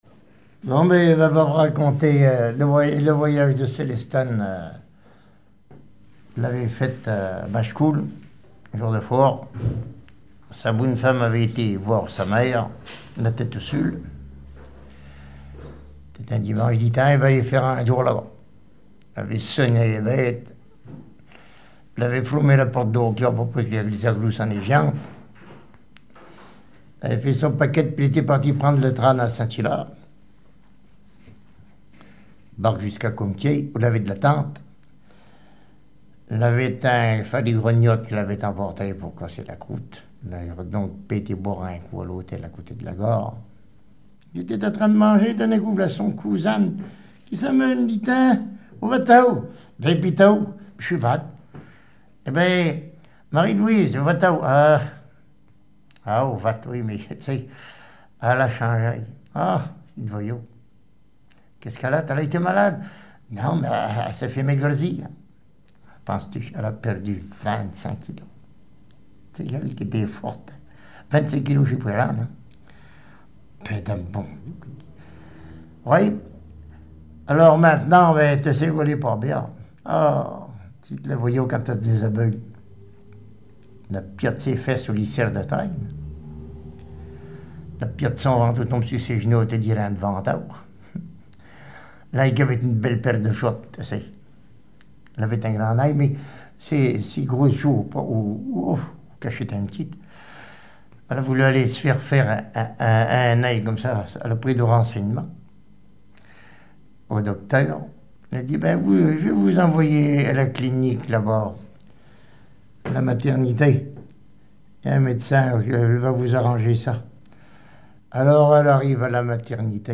Langue Maraîchin
Genre sketch
Catégorie Récit